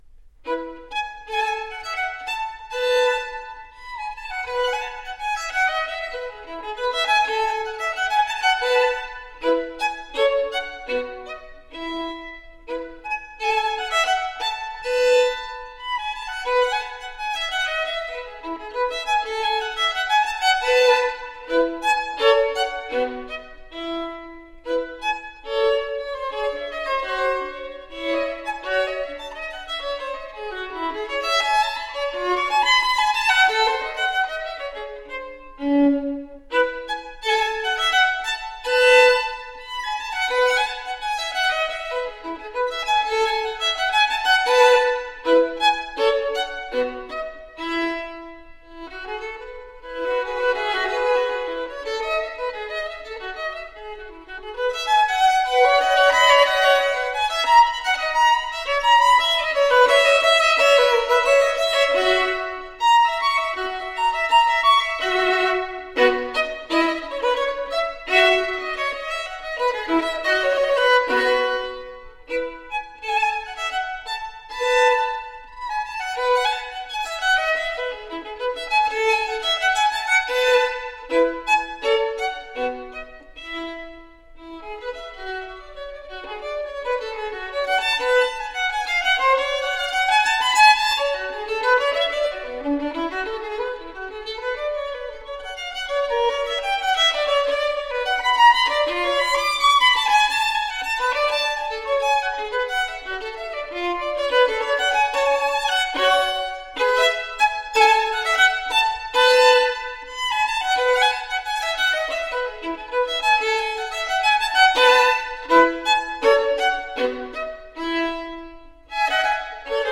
so the resulting music sounds incredibly fresh and alive.
Classical, Baroque, Instrumental